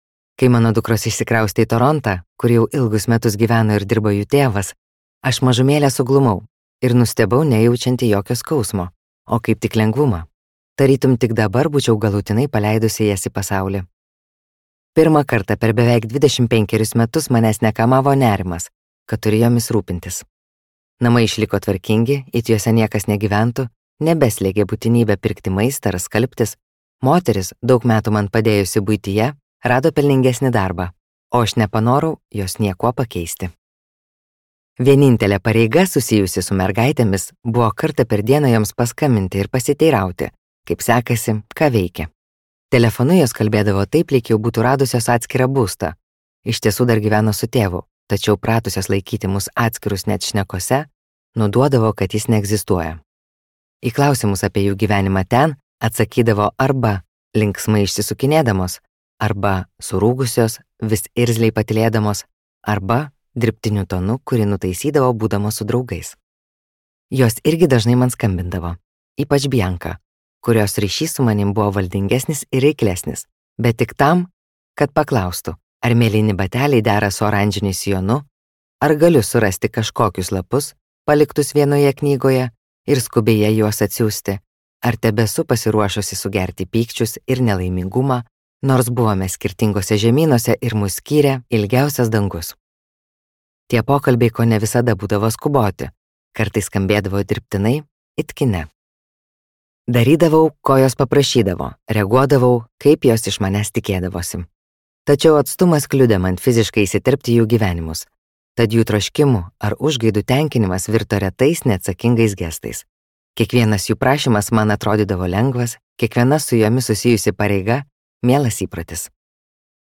Dingusi dukra | Audioknygos | baltos lankos